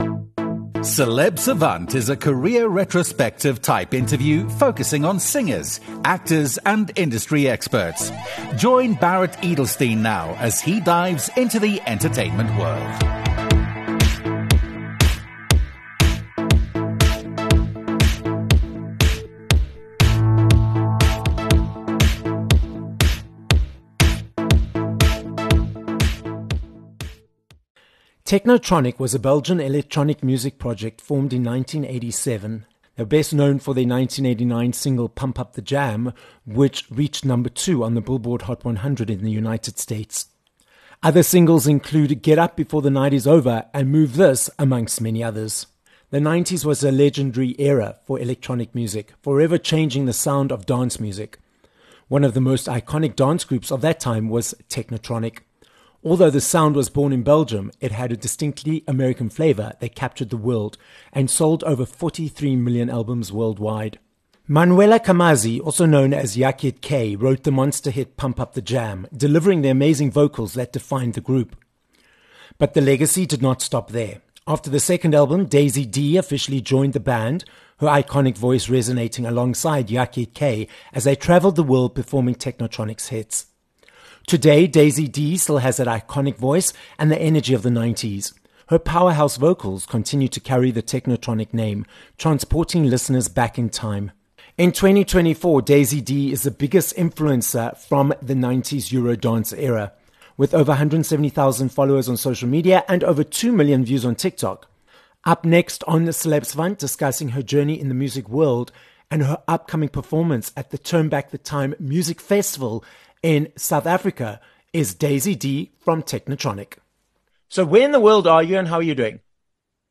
Daisy Dee from Technotronic - a Dutch singer, actress, TV host, and producer - joins us from Belgium on this episode of Celeb Savant. Daisy gives us the details of her journey in the industry, how she became part of '80s and '90s Eurodance act Technotronic, which sold over 43 million records worldwide… and due to their popularity continues to sell out concerts around the world - including the upcoming 'Turn Back The Time' festival in South Africa.